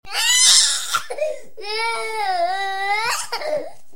cry3.wav